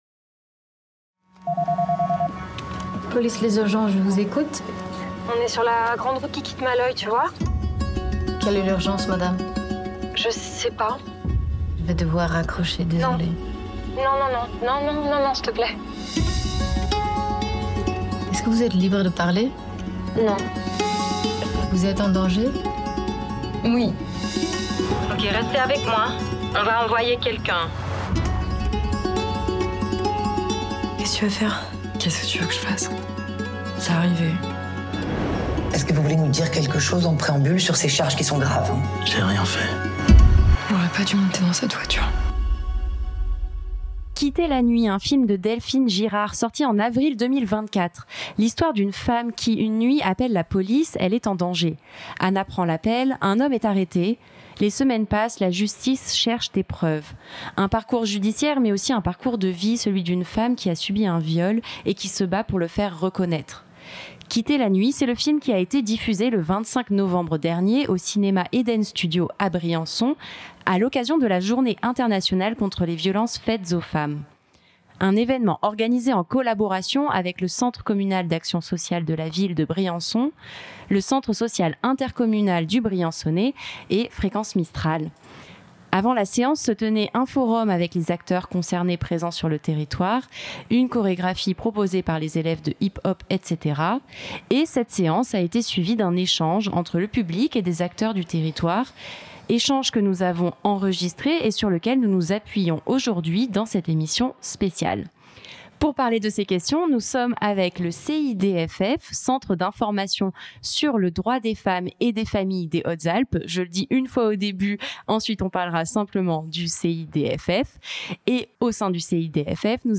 Puis cette séance a été suivie d'un échange entre le public et des acteurs du territoire, échange que nous avons enregistré.
L'émission s'appuie sur la discussion qui s'est déroulée à l'Eden Studio ce 25 novembre 2024.